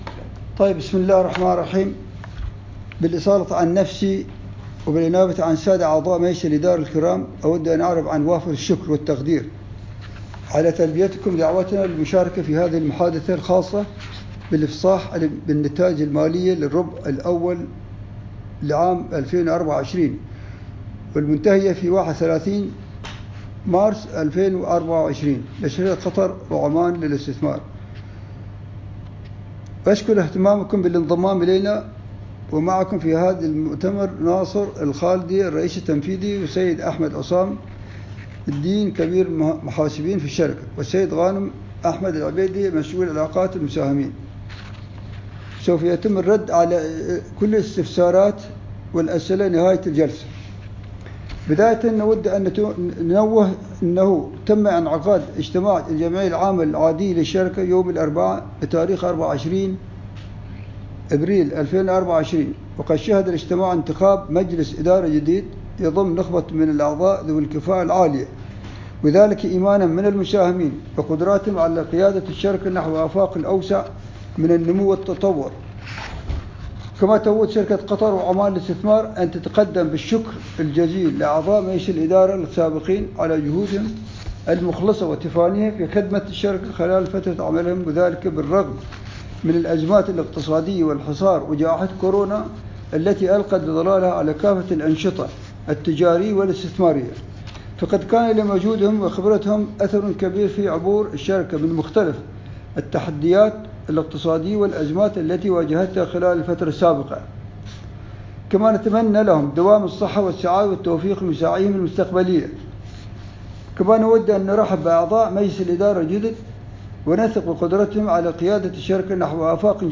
المكالمة الجماعية